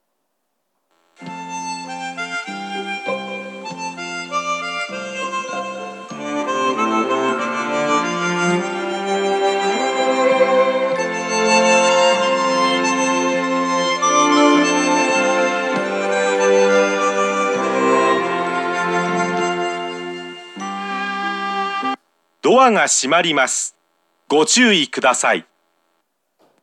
磯原駅　Isohara Station ◆スピーカー：小VOSS
そのため発車メロディは野口雨情が作詞した「七つの子」が使用されていますが、原曲無編集のためフルコーラスは63秒あります。
1番線発車メロディー